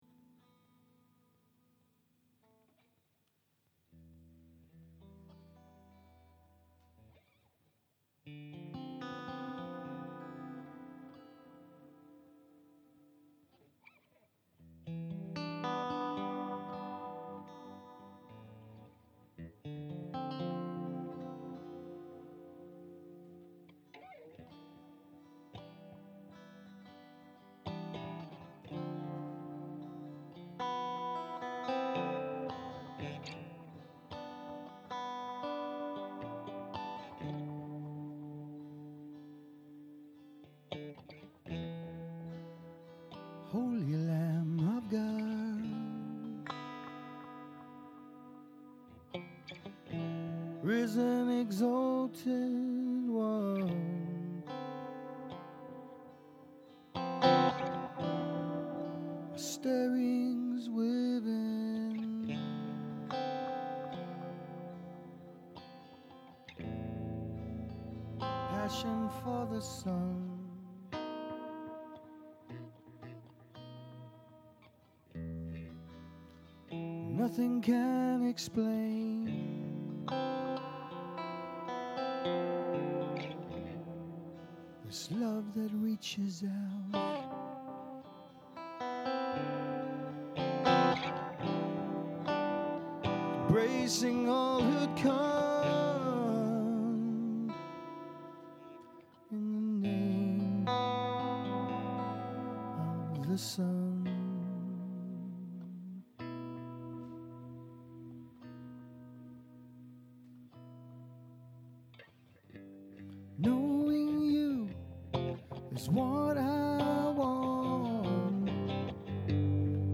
It was recorded at our “Rumours of worship” in August 2010.
plays keys on it and sings backup